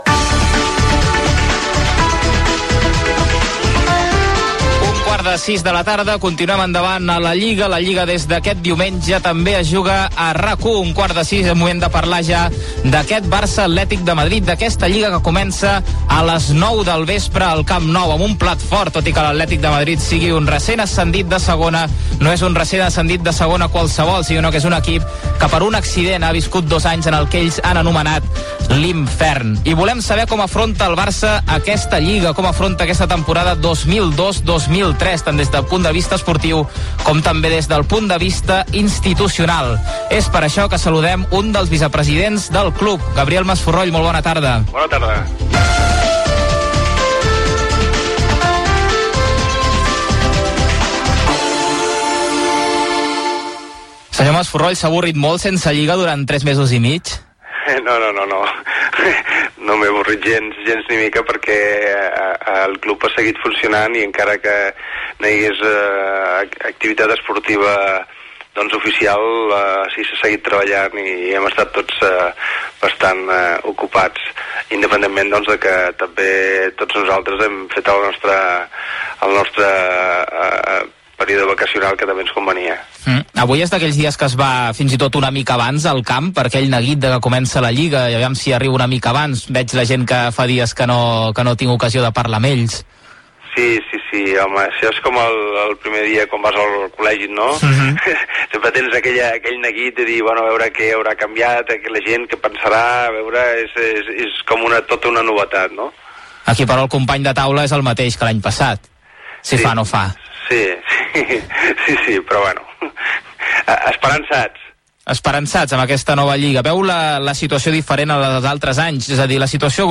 Gènere radiofònic Esportiu
Banda FM